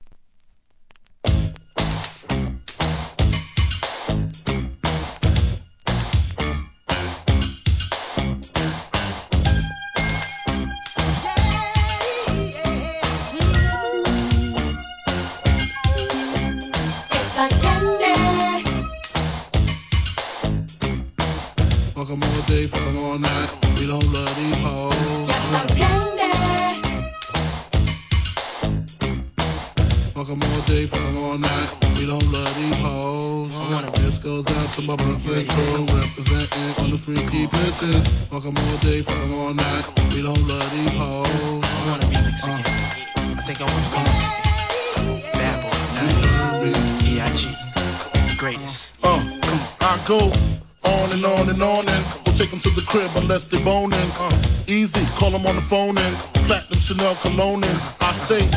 HIP HOP/R&B
REMIX, BLEND物!!